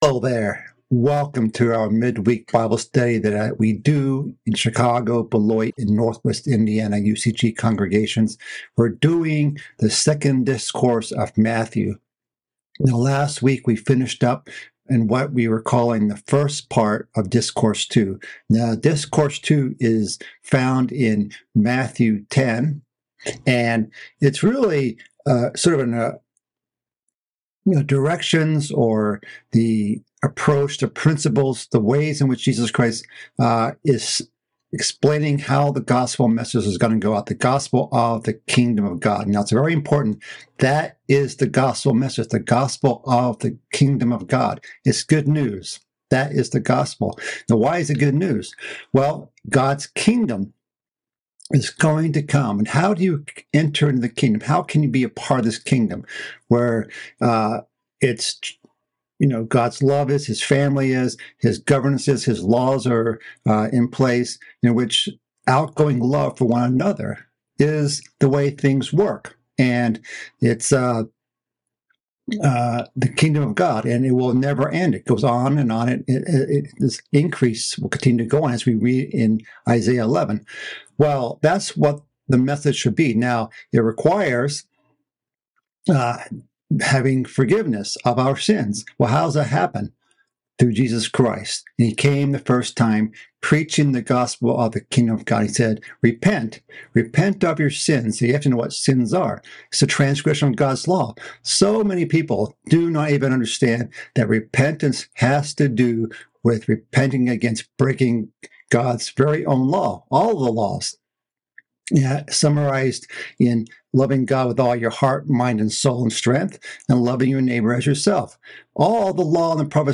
This is the third part of a mid-week Bible study series covering Christ's second discourse in the book of Matthew. This message continues in chapter 10 of Matthew, covering the topic of persecution and being wise as serpents and harmless as doves.